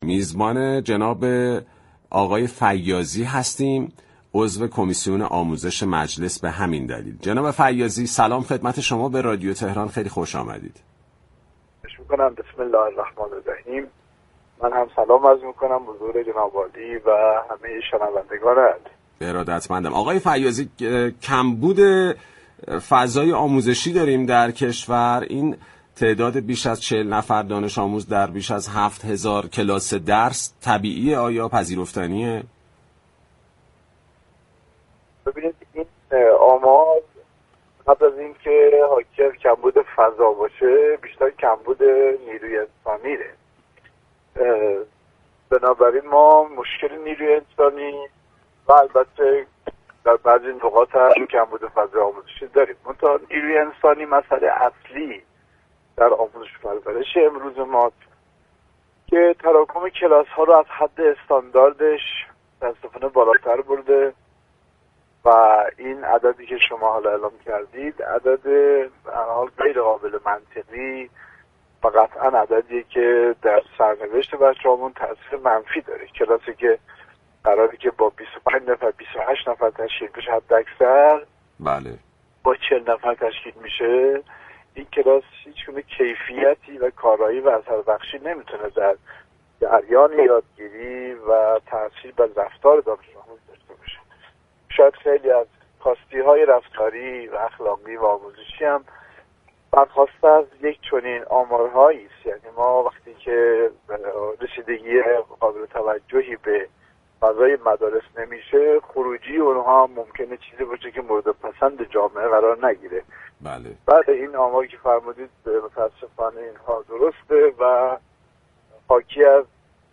عضو كمیسیون آموزش و تحقیقات مجلس در گفت‌و‌گو با رادیو تهران، تراكم بالای كلاس‌های درس را به دلیل كمبود نیروی انسانی دانست و دلیل دیگر را حضور پرشمار دانش‌آموزان اتباع در مدارس ایرانی عنوان كرد.